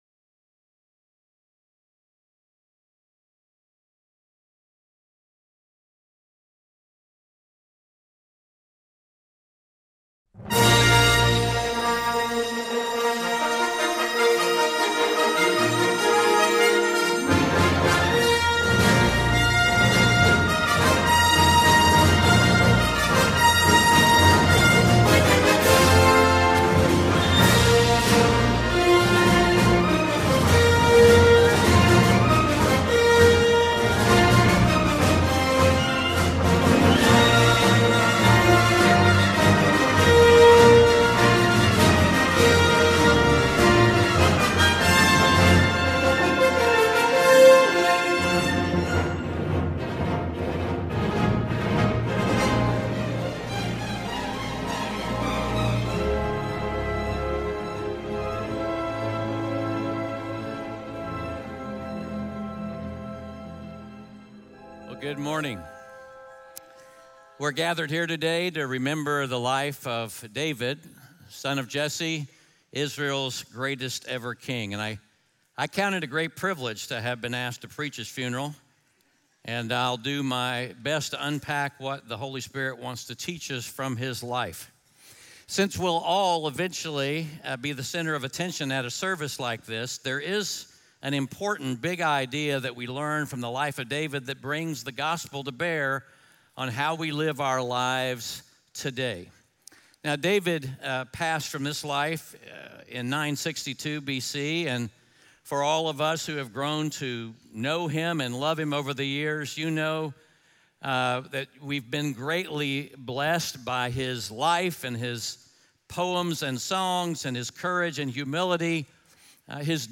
1 Kings 2:1-10 Audio Sermon Notes (PDF) Ask a Question Scripture: 1 Kings 2:1-10 SERMON SUMMARY Today, we come to the final message in part two of our “Royalty” series on the life of David.